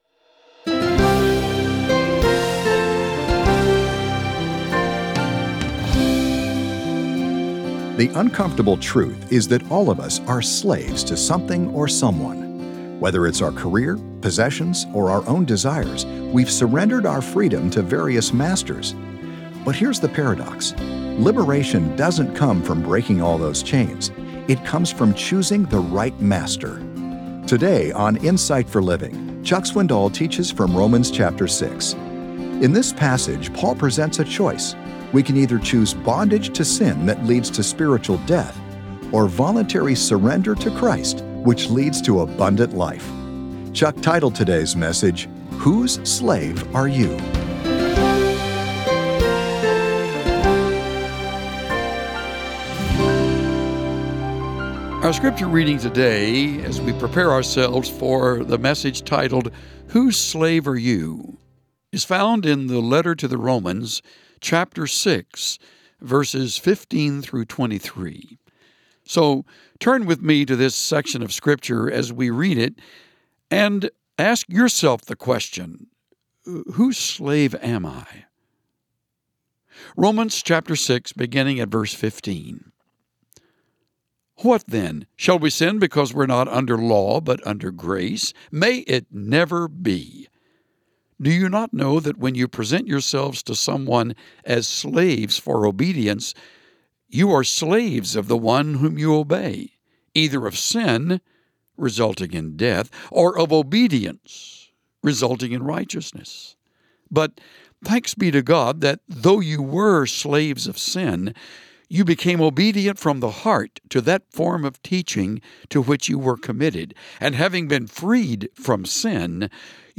Tune in to hear Pastor Chuck Swindoll teach on sin, grace, and sanctification from Romans 6.